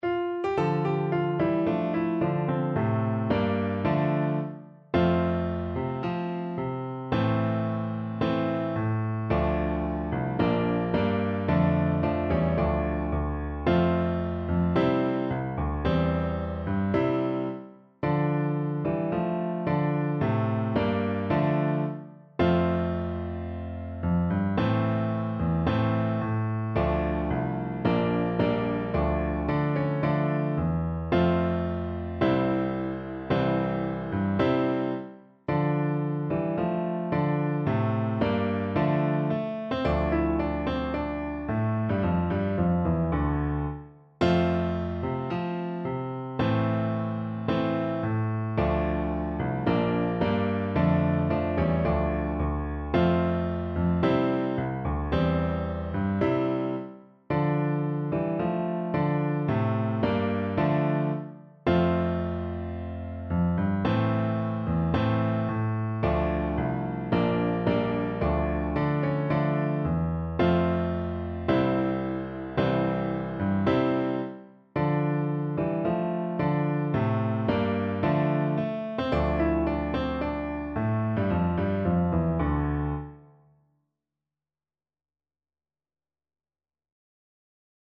~ = 110 Allegro (View more music marked Allegro)
Traditional (View more Traditional Flute Music)